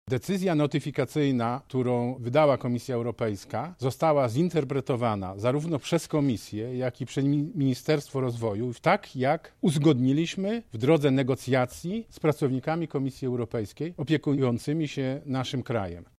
– mówi Sławomir Sosnowski, marszałek województwa